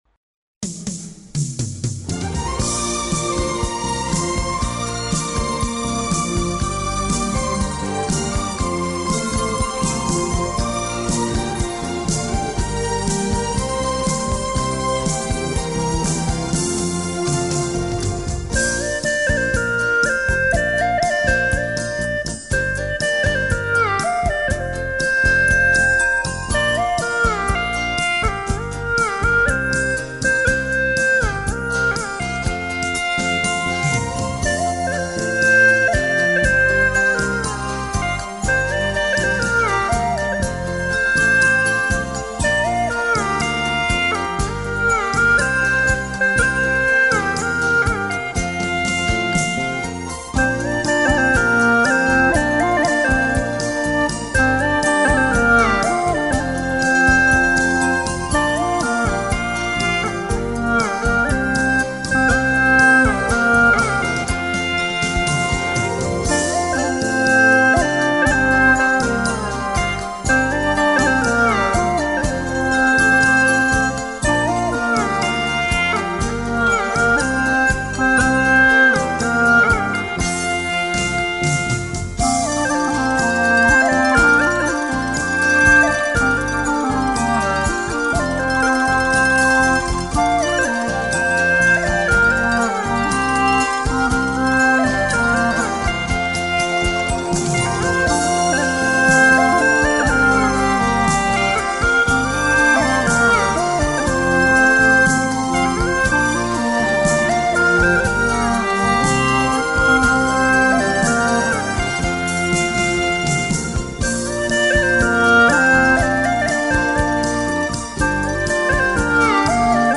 调式 : 降B 曲类 : 民族